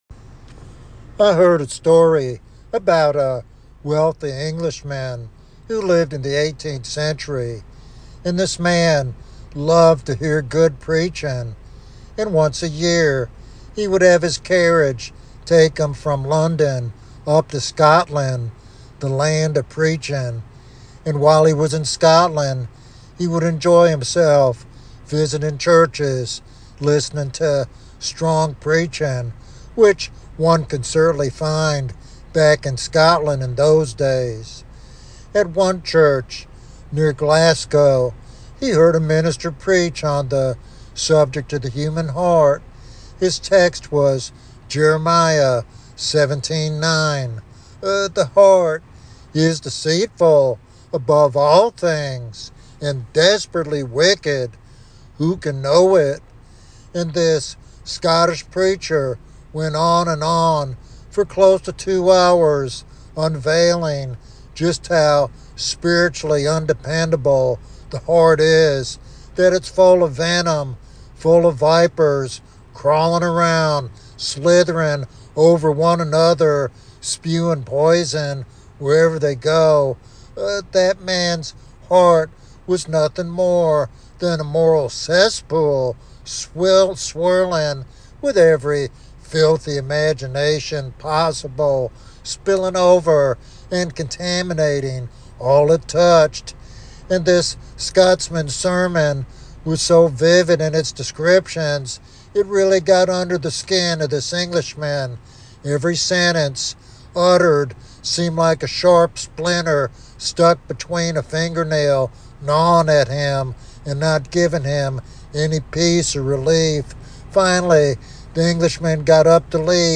He emphasizes the necessity of relying completely on Jesus Christ to overcome temptation and live faithfully. This sermon calls Christians to vigilance, repentance, and deeper intimacy with God as the only way to guard against the heart's destructive tendencies.